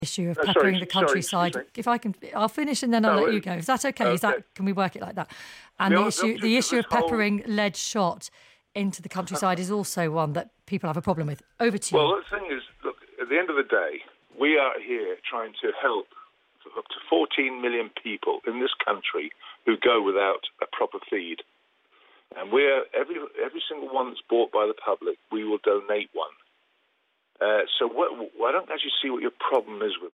Ian Botham's ‘Car Crash’ BBC Radio 5 Live Interview